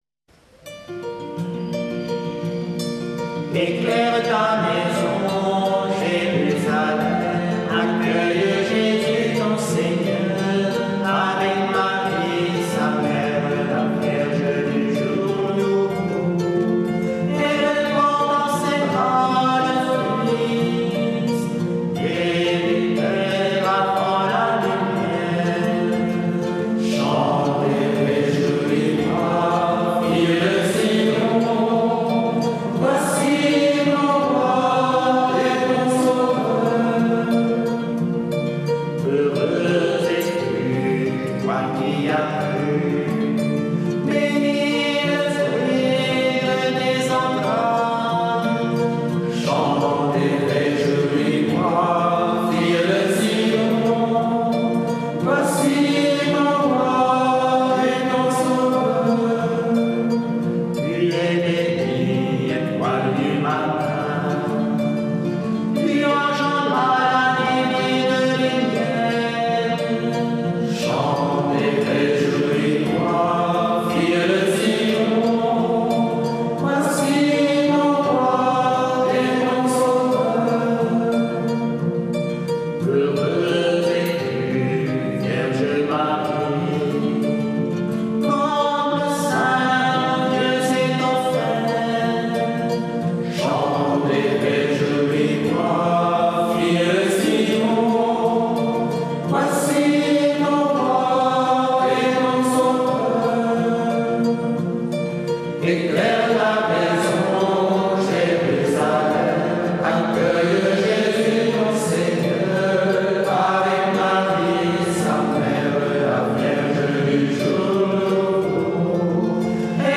Prière du matin
Groupes de prière